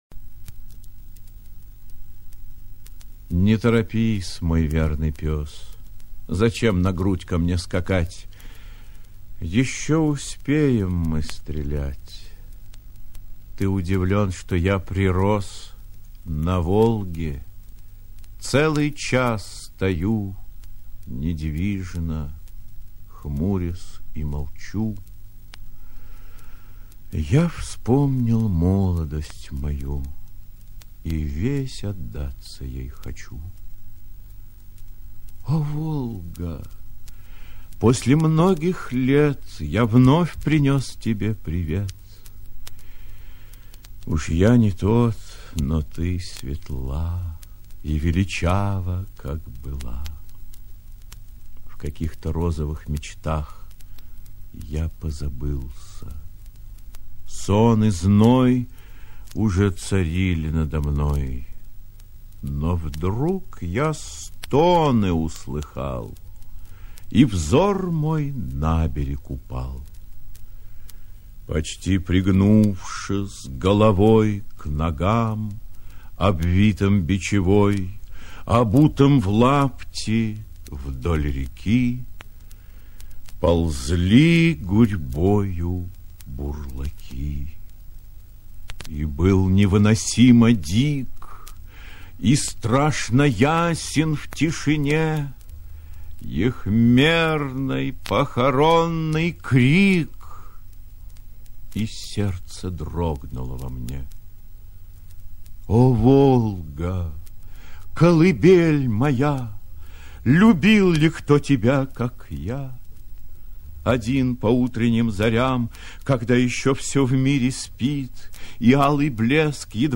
1. «Н.Некрасов – На Волге ( чит.А.Консовский)» /
Nekrasov-Na-Volge-chit.A.Konsovskij-stih-club-ru.mp3